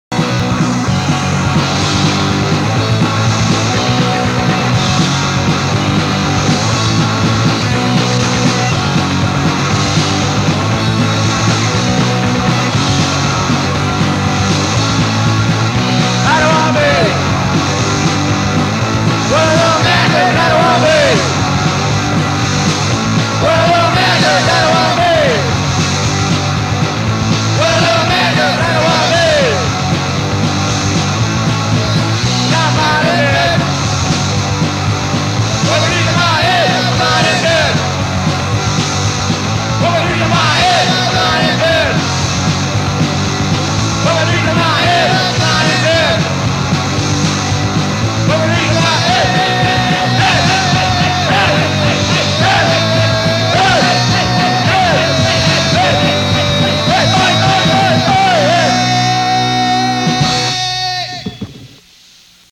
Guitar/Vocals
Bass
Drums
Punk Tags